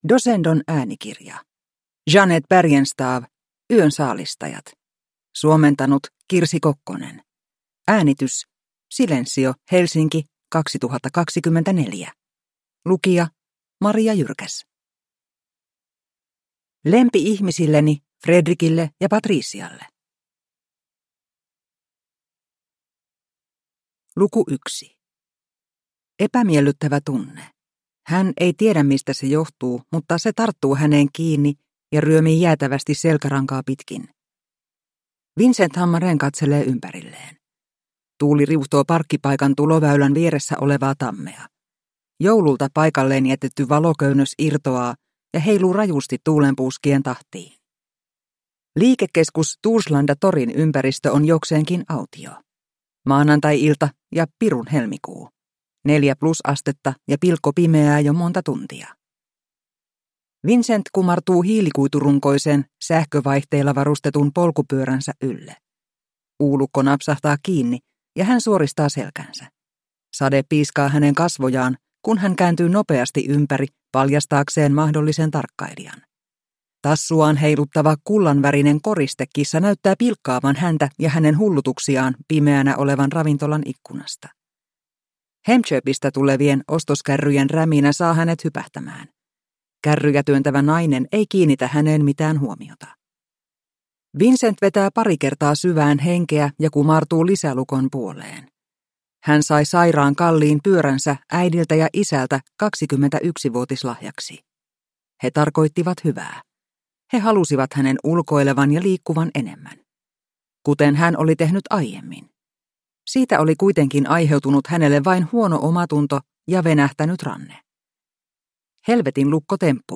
Yön saalistajat (ljudbok) av Jeanette Bergenstav